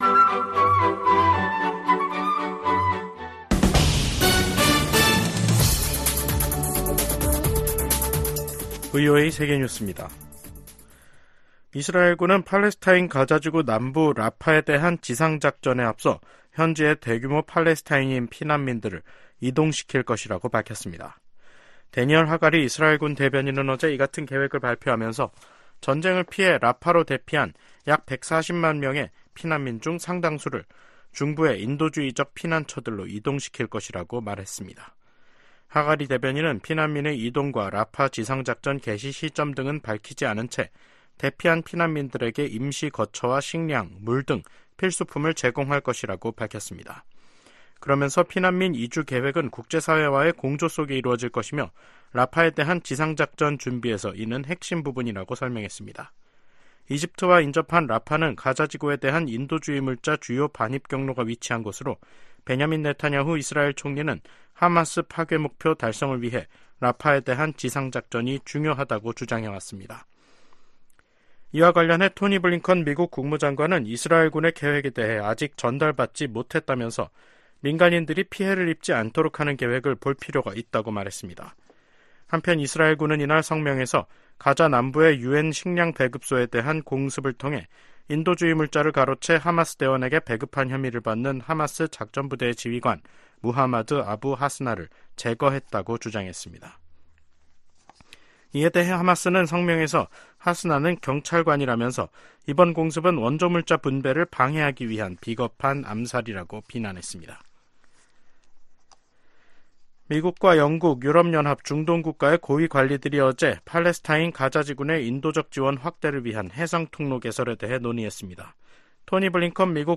세계 뉴스와 함께 미국의 모든 것을 소개하는 '생방송 여기는 워싱턴입니다', 2024년 3월 14일 저녁 방송입니다. '지구촌 오늘'에서는 유럽의회가 세계 최초 인공지능(AI) 규제법안을 승인한 소식 전해드리고, '아메리카 나우'에서는 중국 기업 '바이트댄스'가 소유한 동영상 공유 플랫폼 '틱톡'을 매각하도록 강제하는 법안이 하원을 통과한 이야기 살펴보겠습니다.